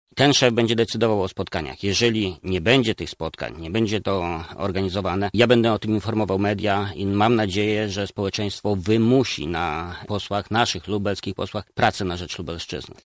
Jak przekonuje senator Jacek Bury, który był gościem Porannej Rozmowy Radia Centrum – „jeśli zostałby nim ktoś z Prawa i Sprawiedliwości, to i tak będę pracował w tym zespole”: